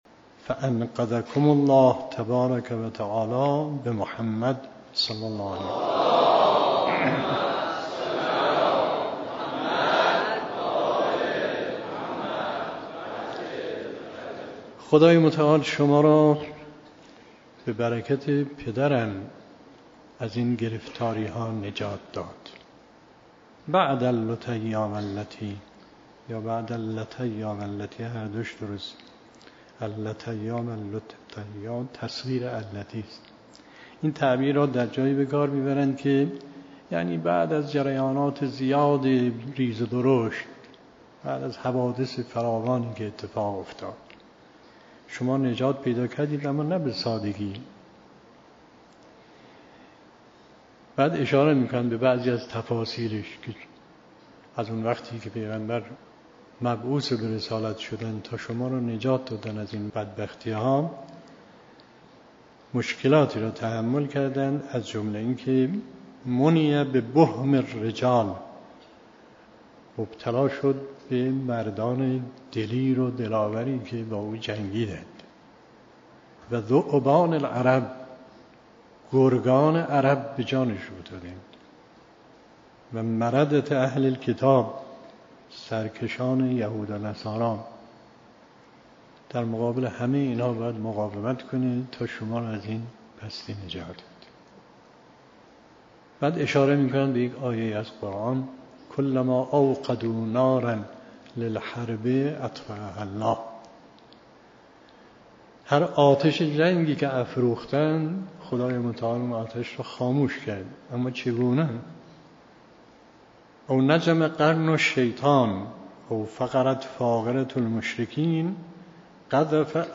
به گزارش خبرگزاری حوزه، به مناسبت ایام فاطمیه، گزیده‌ای از بیانات مرحوم آیت الله مصباح در رابطه با خطبه فدکیه حضرت زهرا سلام الله علیها تقدیم شما فرهیختگان می‌شود.